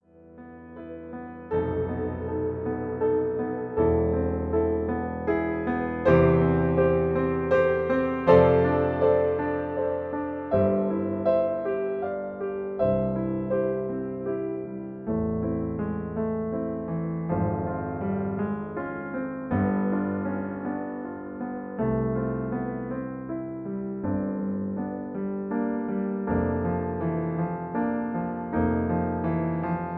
MP3 piano accompaniment
in C Major